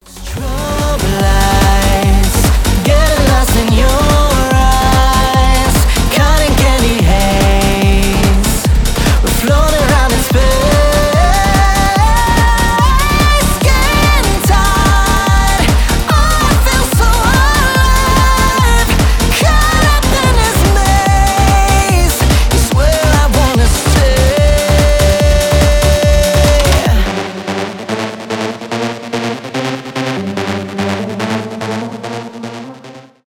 dance pop , клубные , поп
electropop
техно , tech house